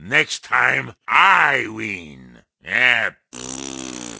Wario says, "Next time, I win! Yeah!" and then blows a raspberry in Mario Kart Wii.
Wario_(Lose_3)_Mario_Kart_Wii.oga